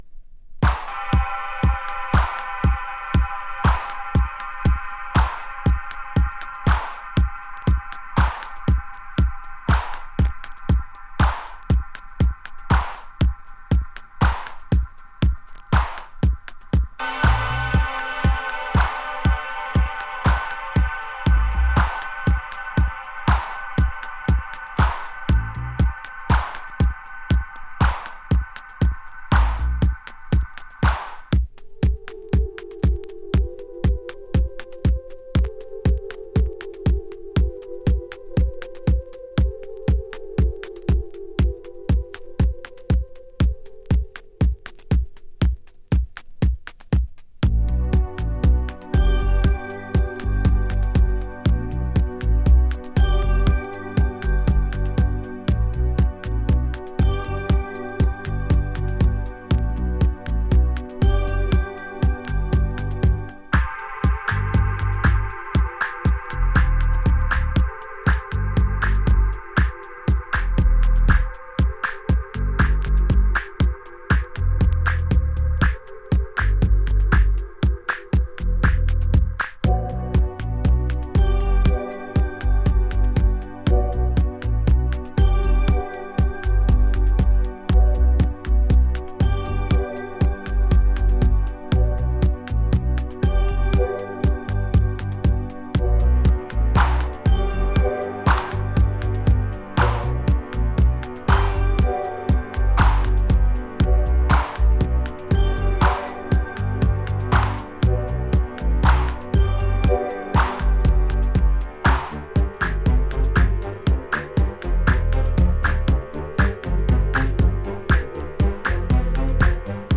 ニューウェイヴ・ディスコ！
スリキズによるごく僅かなチリノイズ箇所有り。